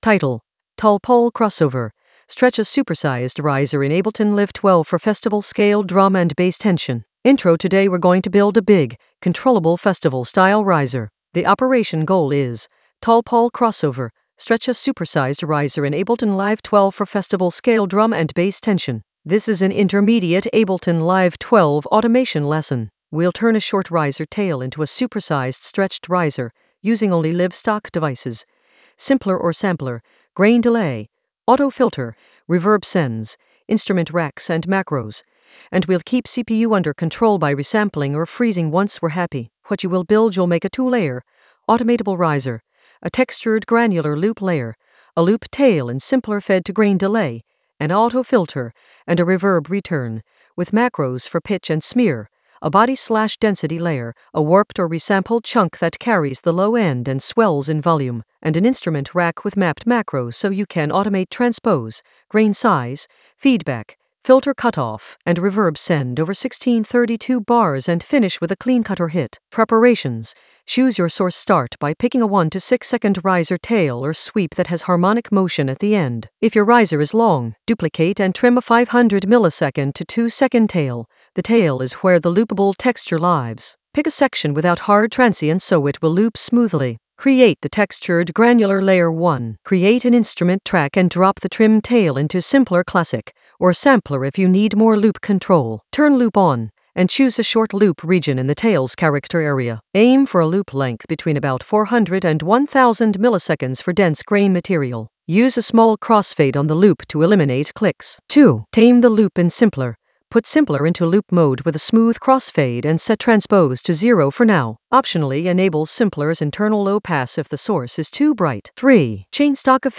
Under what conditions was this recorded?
The voice track includes the tutorial plus extra teacher commentary.